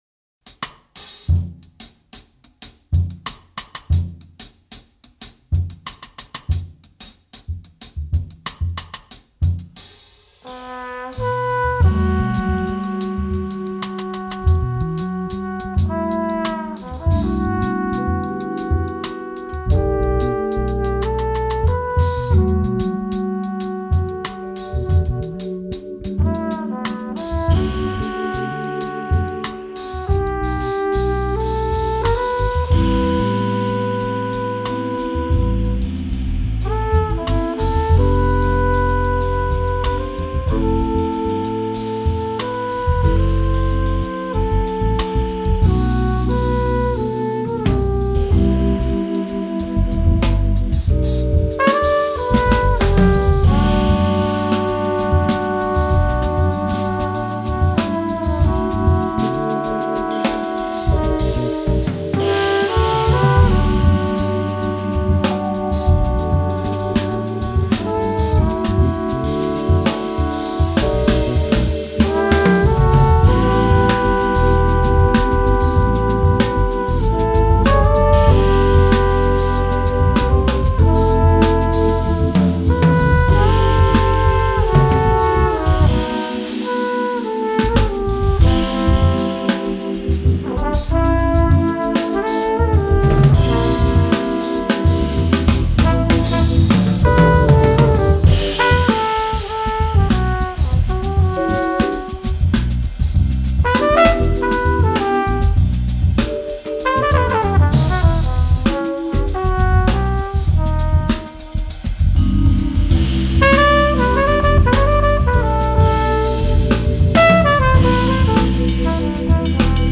bass
Vibraphones
drums
Trumpet